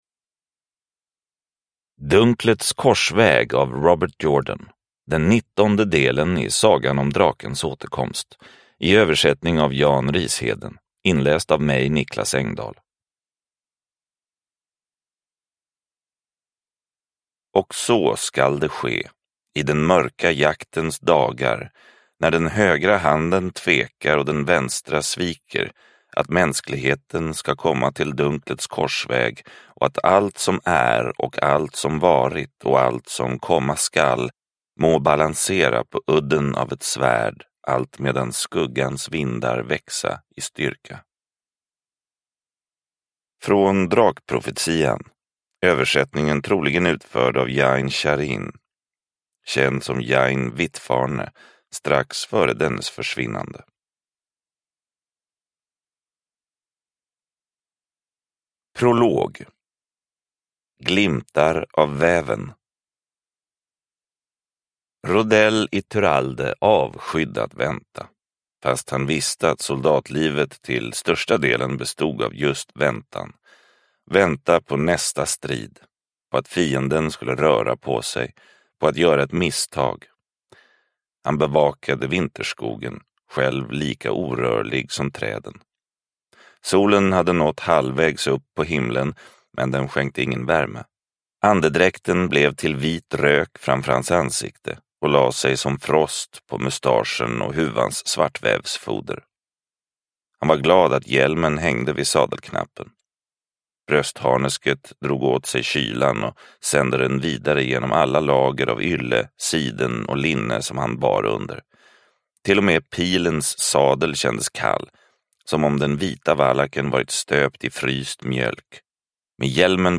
Dunklets korsväg – Ljudbok – Laddas ner